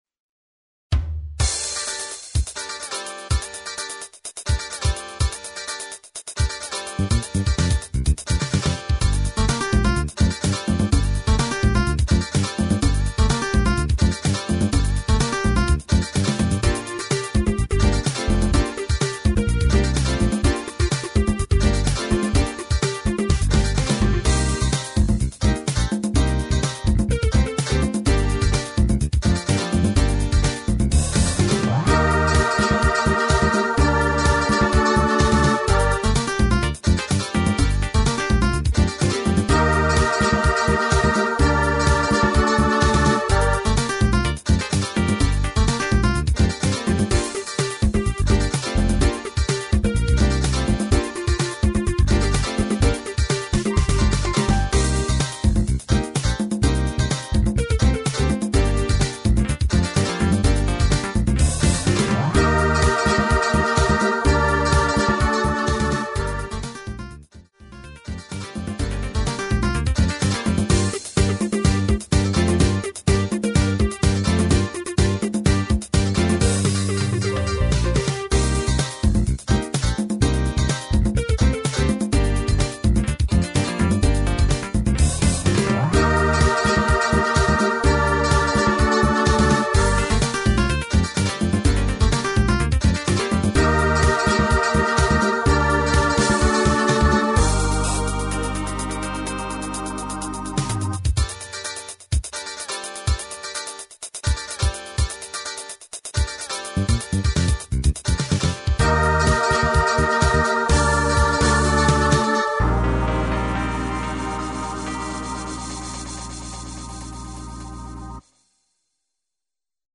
Instrumental TRacks